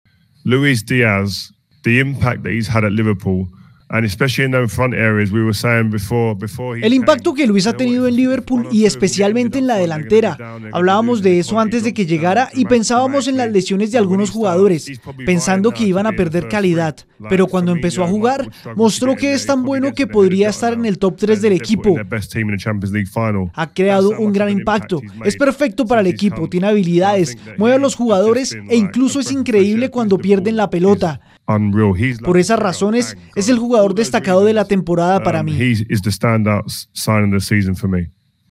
(Rio Ferdinand en su canal de YouTube)